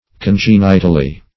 congenitally - definition of congenitally - synonyms, pronunciation, spelling from Free Dictionary Search Result for " congenitally" : The Collaborative International Dictionary of English v.0.48: Congenitally \Con*gen"i*tal*ly\, adv. In a congenital manner.